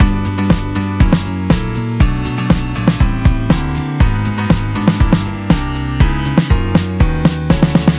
A:コードだけがんばった音楽
Aの方は、和音の並びを聴けば確かにオシャレですが、全体的なプロダクションのレベルとしては低いと言わざるを得ません。音の強弱伸ばす/切る打点のチョイスなどひとつひとつの選択が練られておらず、音楽の魅力がやや削がれてしまっています。